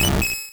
Cri de Mélofée dans Pokémon Rouge et Bleu.